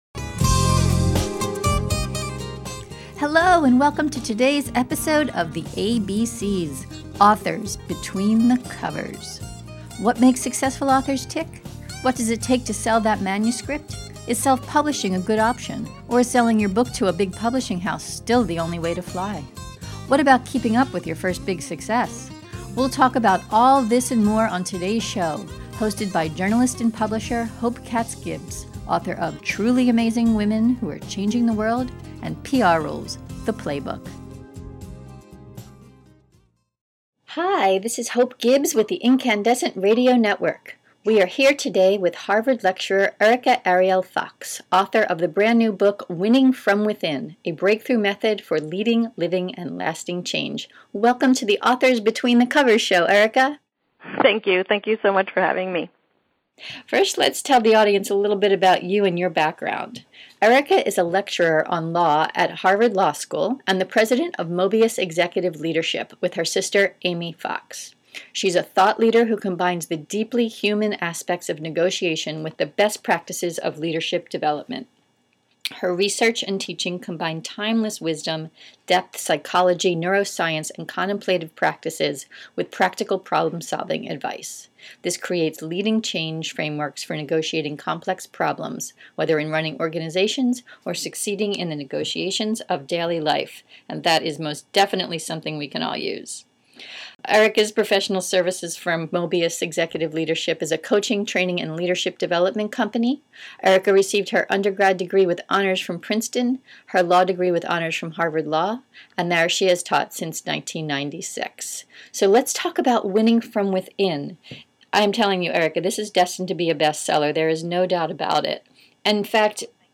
Path: Grow with Your Voyager Download the podcast interview today, at right.